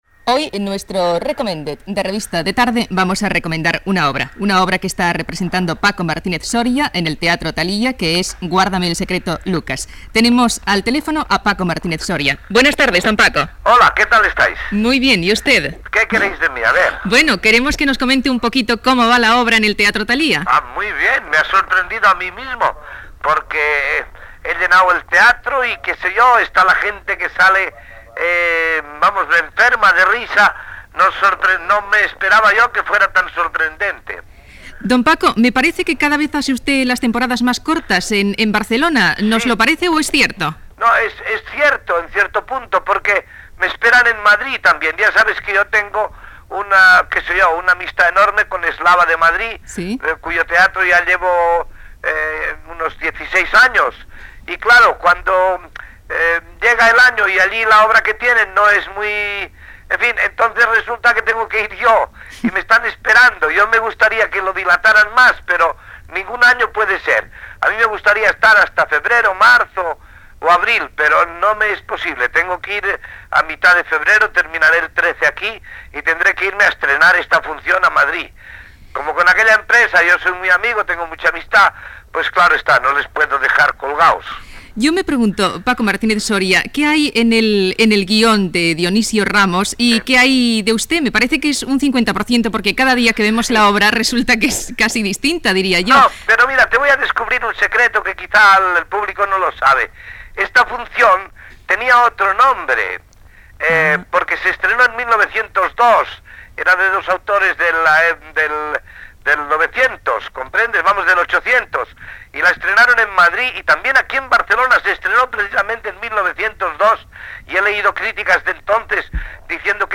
Secció "Recomended", amb una entrevista a l'actor Paco Martínez Soria que actua al teatre Talia de Barcelona
Entreteniment